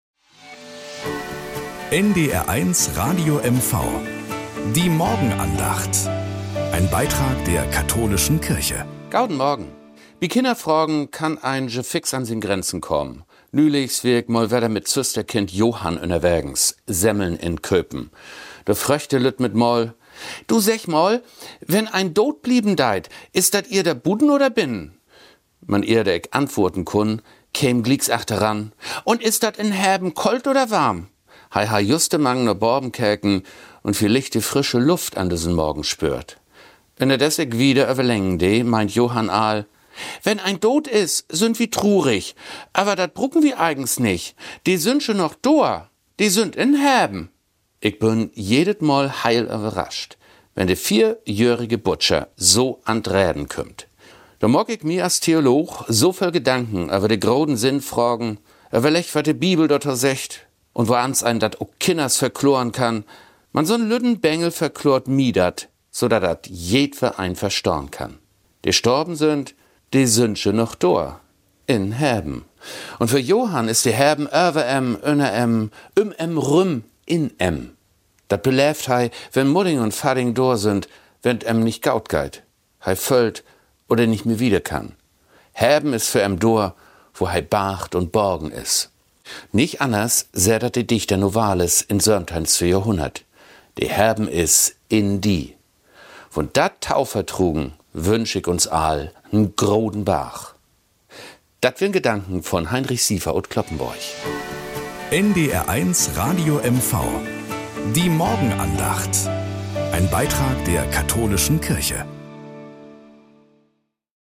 Nachrichten aus Mecklenburg-Vorpommern - 02.06.2025